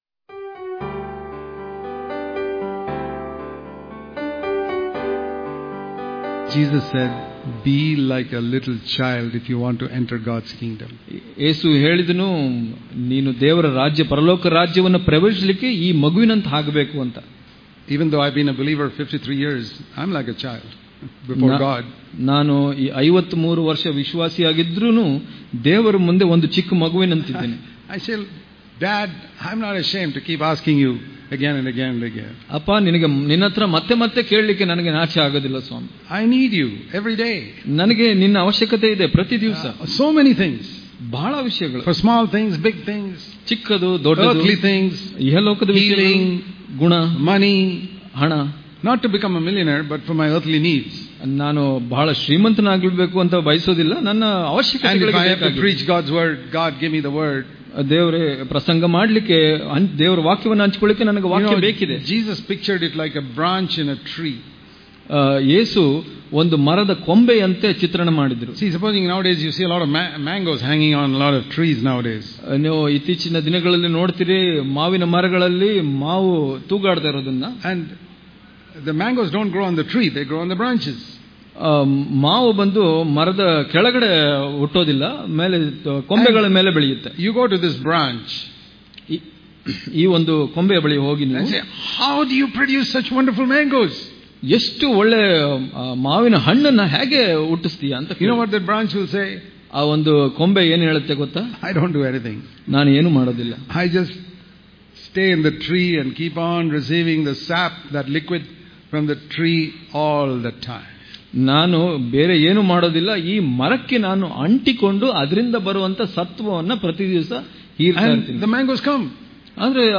April 25 | Kannada Daily Devotion | Christian Life A Burden Or A Joy To You?